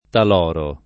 [ tal 0 ro ]